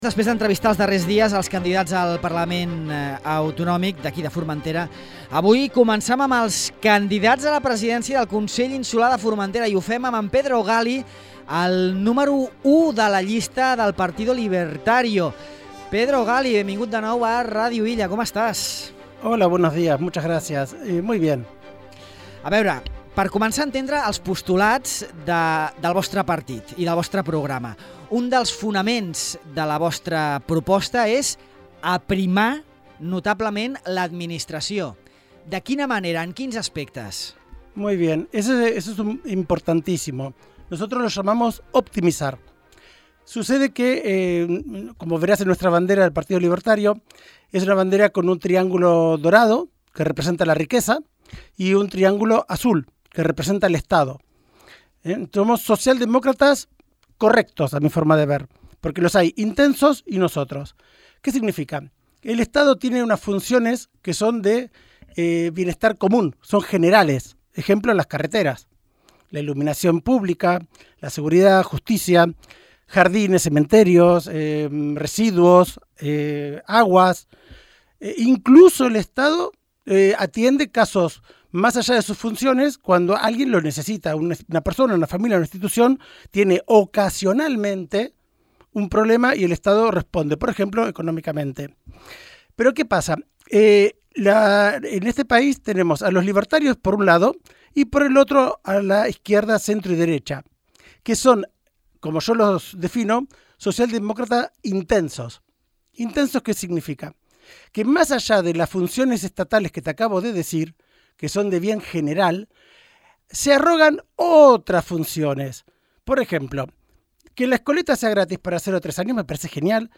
entrevista a Ràdio Illa